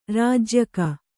♪ rājyaka